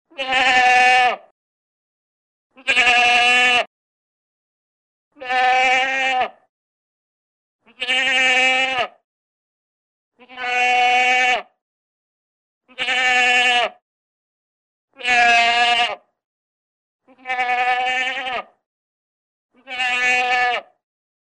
Звуки козы
Звук козлиного блеяния